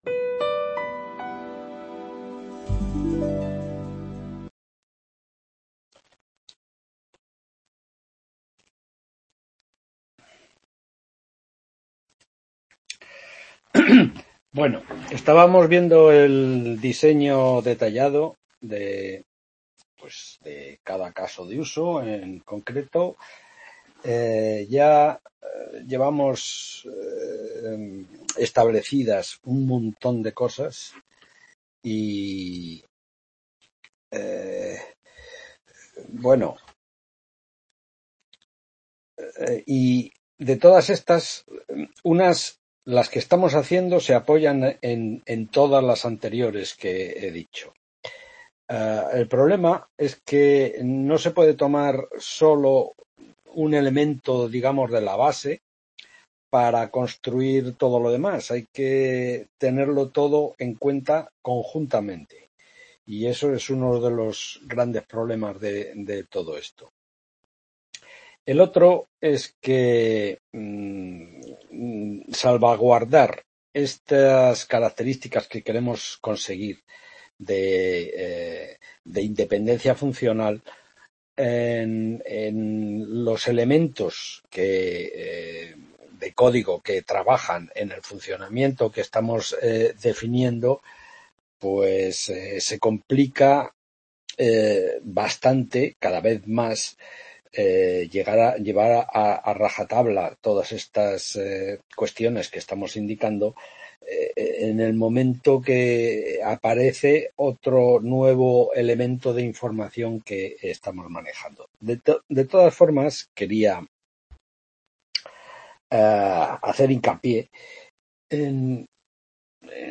11ª Tutoría Diseño de Software.